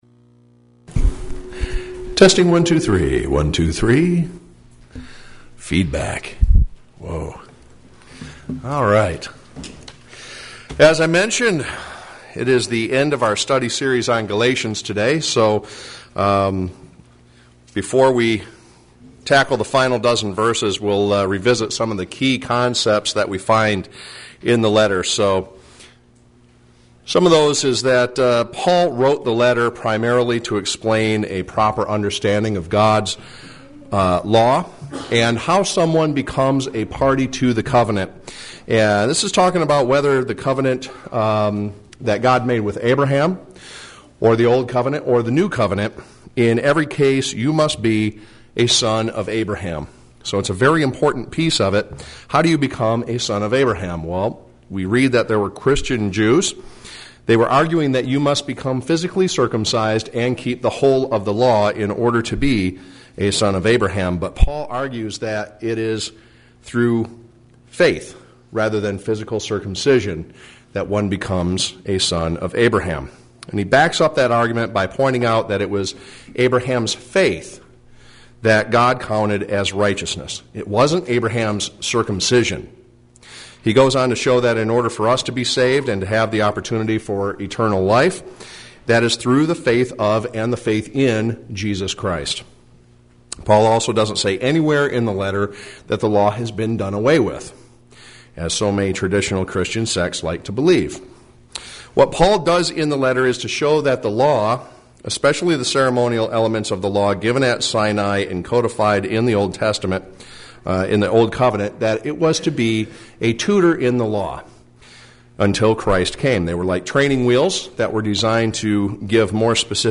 Sermon
Given in Flint, MI